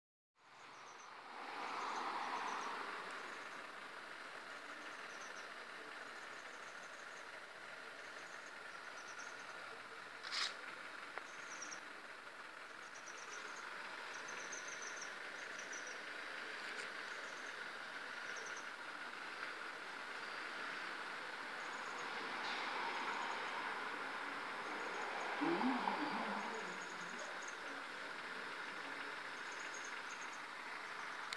Pacific Pygmy Owl (Glaucidium peruanum)
2017-04-05-Peruvian-pygmy-owl.m4a.mp3
Detailed location: San Isidro - Huaca Huallamarca
Condition: Wild
Certainty: Observed, Recorded vocal